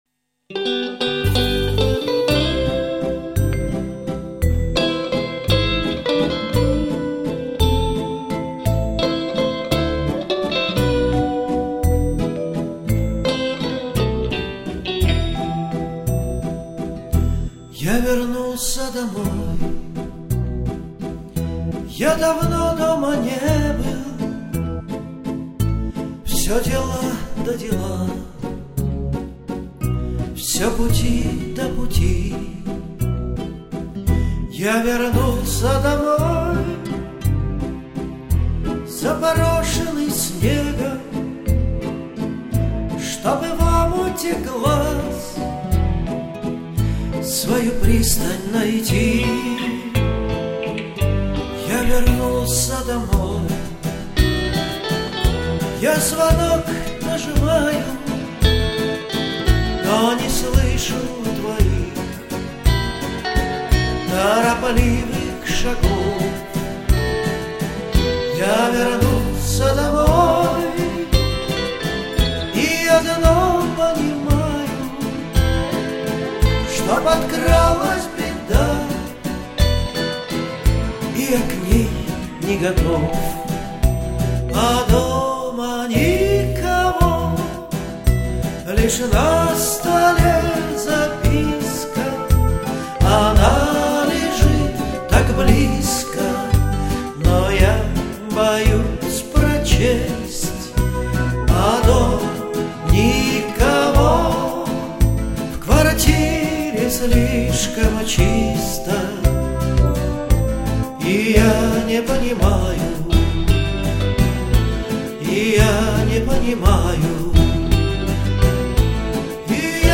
с более напряженной грустной лирической интригой